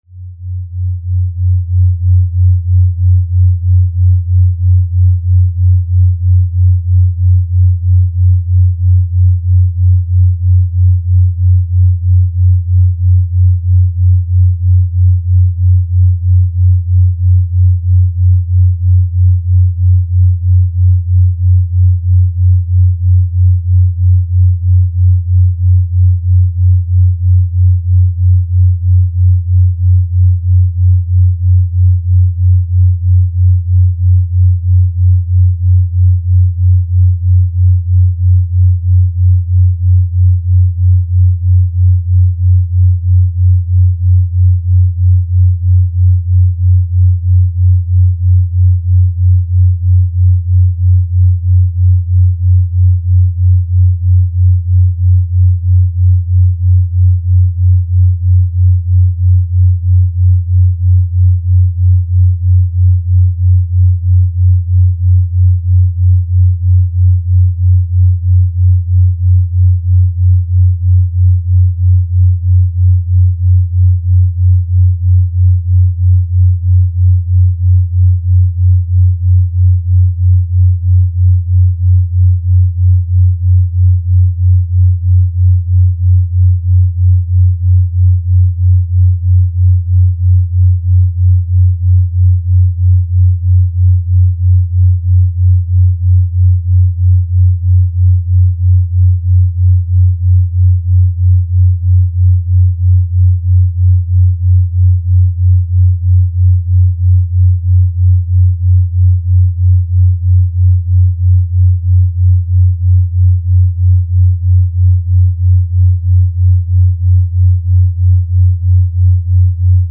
When the hum briefly pulses louder, Sun and Moon are in phase (spring tide); when it briefly fades, Sun and Moon are out of phase (neap tide).
The final sound clip is an extended version of this pulsating tone, here simulated by computer to reflect the tidal pulls of Sun and Moon over a 25-year period. (If you’re so inclined, you can count off the months as you listen: one cycle of two loud pulses = 1 lunar month.)
I find this a very soothing sound.